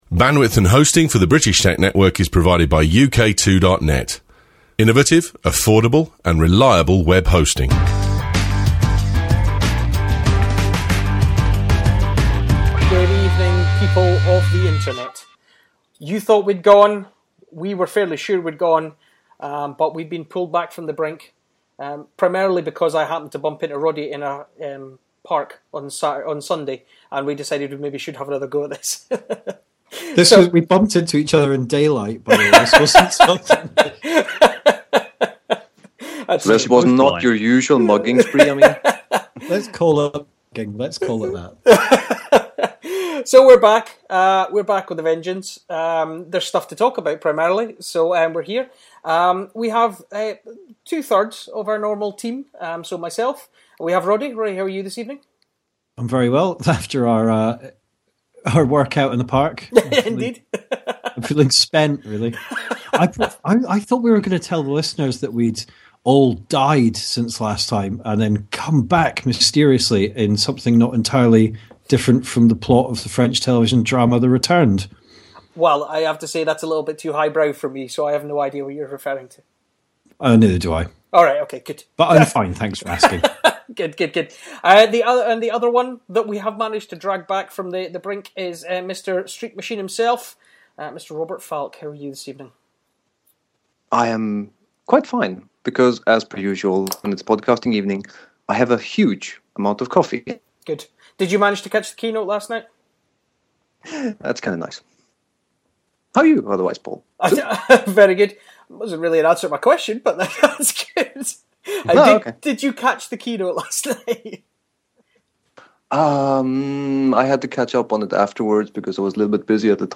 The guys come together once again to discuss all the happenings during the WWDC keynote and all the creamy goodness of iOS7